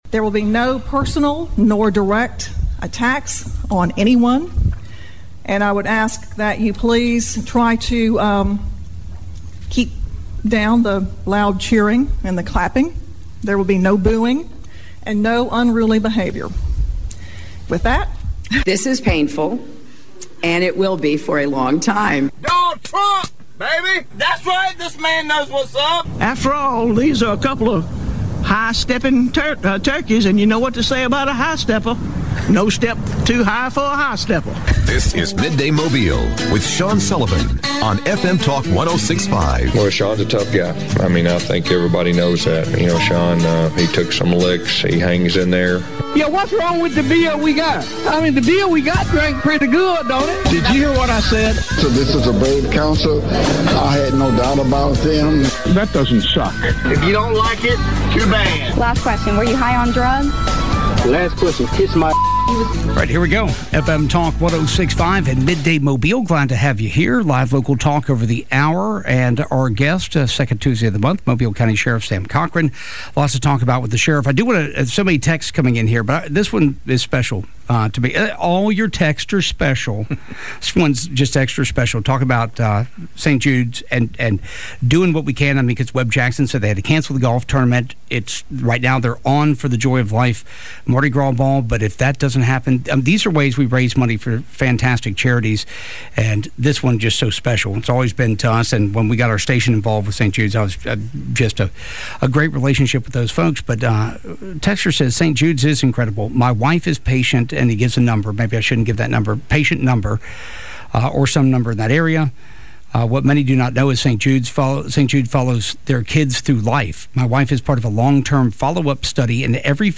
Ask the Sheriff with Mobile County Sheriff Sam Cochran - Midday Mobile - September 8 2020